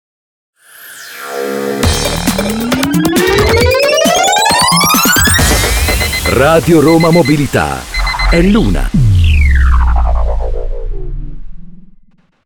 TOP-ORA-1-MASC-RRM.mp3